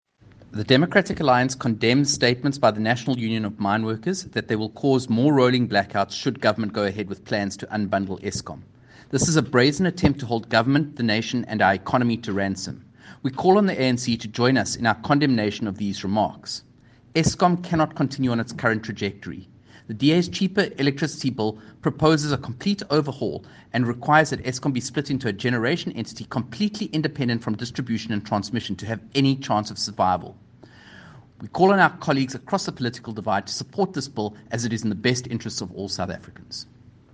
soundbite in English by Kevin Mileham MP, DA Shadow Minister of Mineral Resources and Energy.